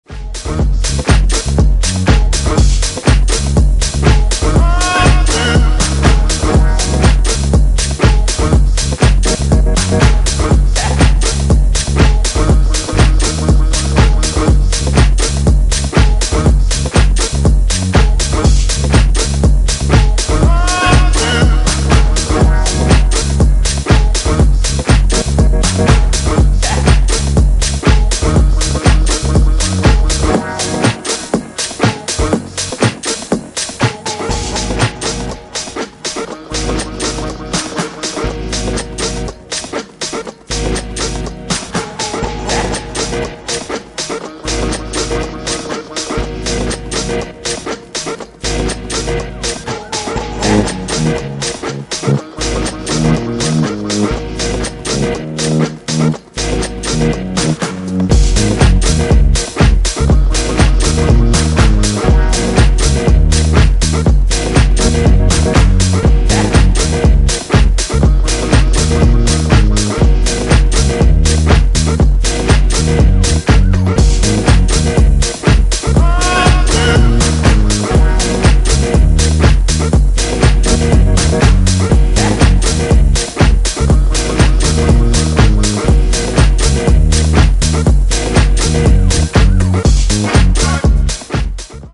ジャンル(スタイル) DISCO HOUSE / DEEP HOUSE / RE-EDIT